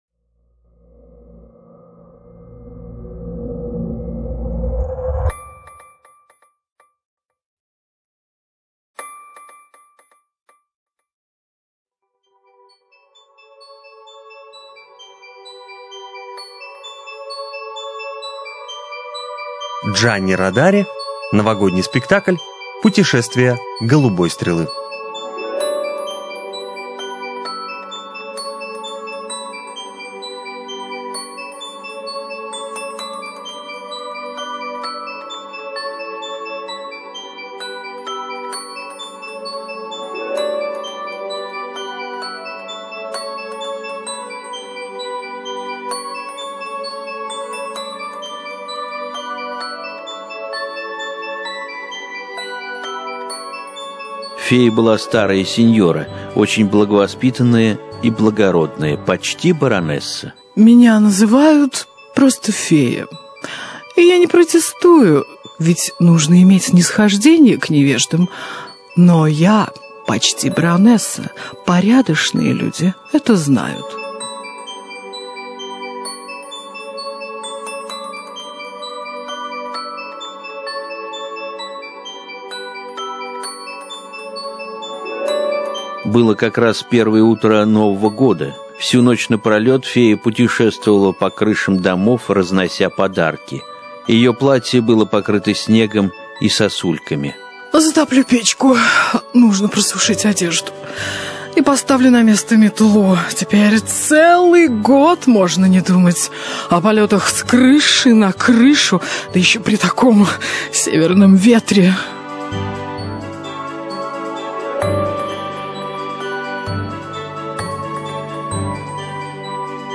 ЖанрДетский радиоспектакль
Студия звукозаписиРадио России Нижний Новгород